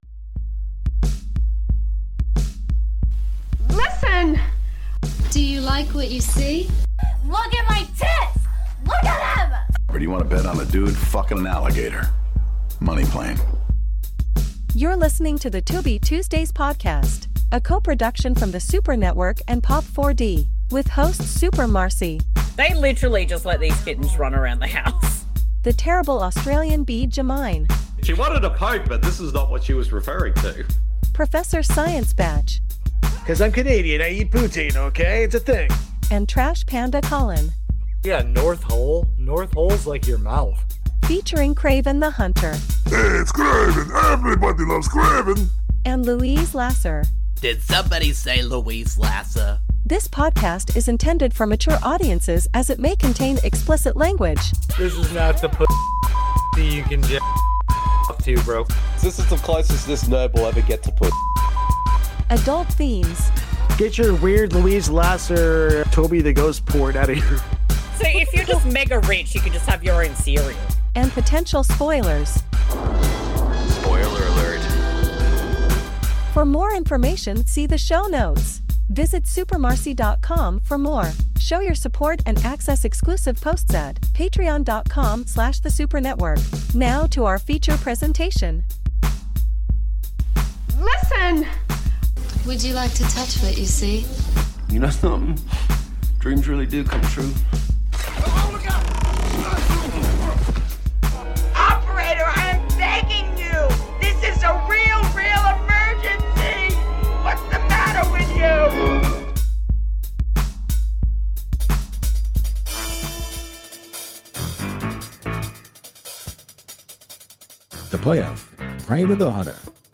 This podcast series is focused on discovering and doing commentaries/watch a longs for films found on the free streaming service Tubi, at TubiTV
Welcome back to The Tubi Tuesdays Podcast, the number one Tubi related podcast that’s hosted by two Australians, one Canadian and one American!